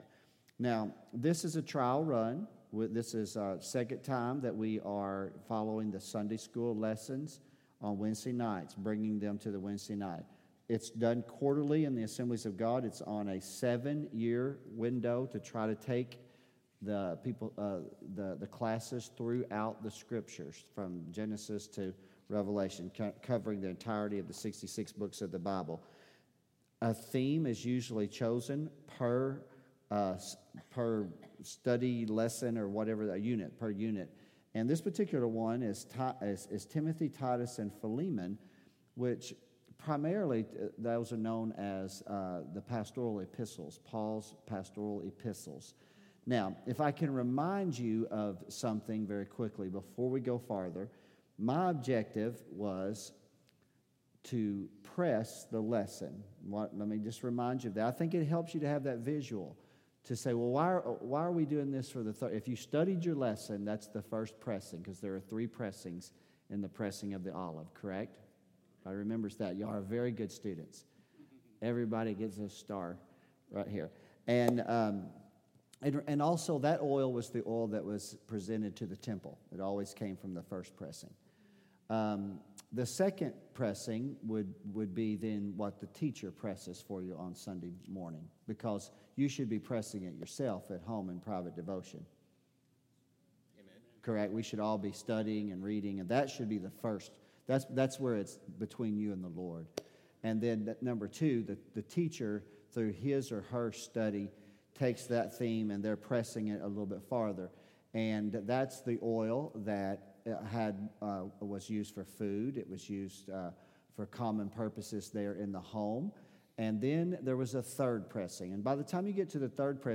Teaching on Church Leadership